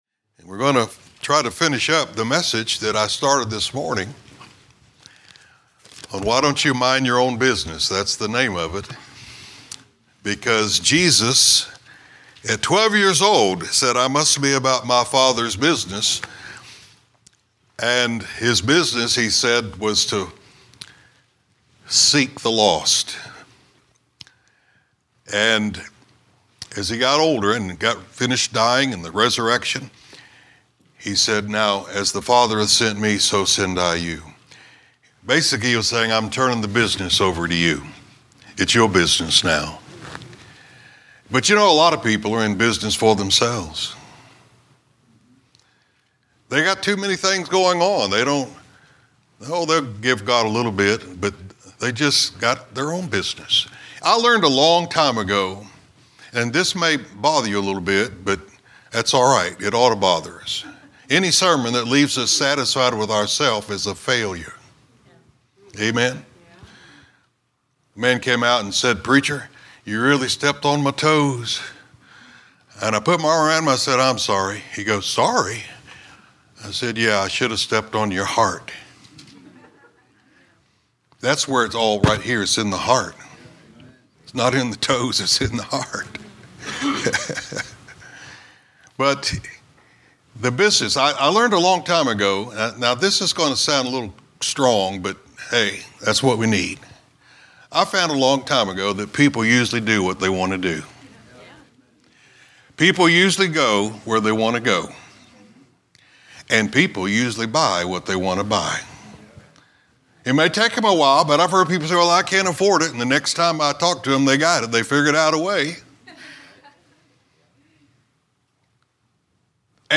A message from the series "General Preaching."